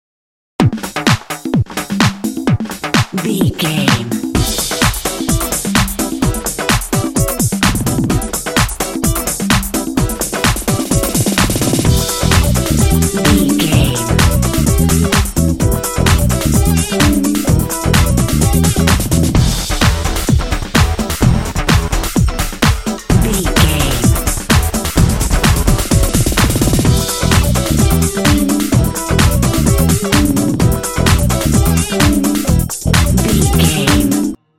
Uplifting
Aeolian/Minor
Fast
bouncy
groovy
drum machine
electric guitar